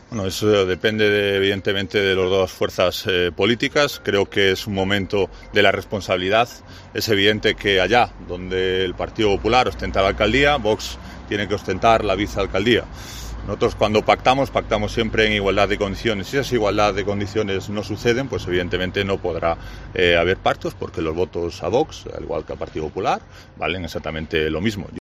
José Ángel Antelo, portavoz de VOX
Durante un acto en Lorca, al que ha acudido en calidad de vicepresidente de la Comunidad, ha dicho que ahora debe “imperar el raciocinio” para que prospere esa negociación, en la que Vox también reclama la inclusión de sus cuatro concejales en el gobierno del PP.